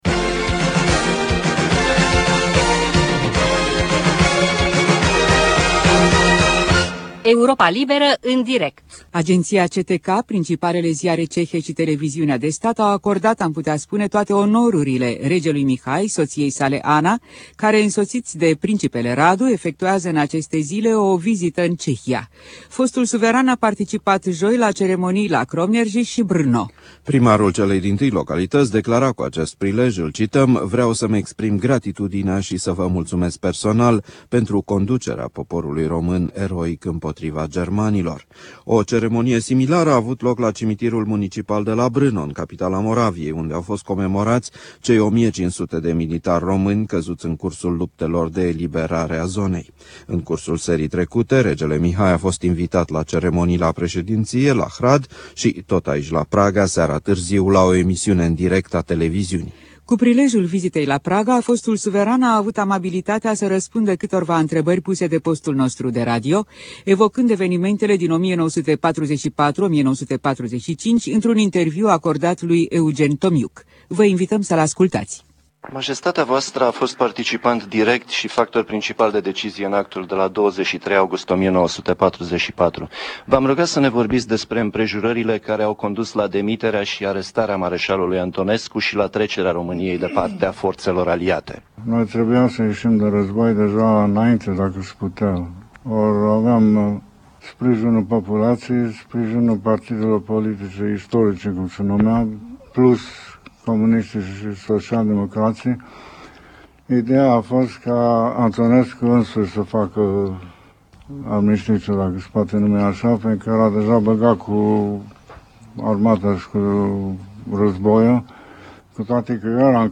Extrase din amintiri, mesaje și interviuri ale regelui Mihai păstrate în Fonoteca și Arhiva istorică Radio Europa Liberă.
Mesajul regelui Mihai prilejuit de declanșarea revoluției române la Timișoara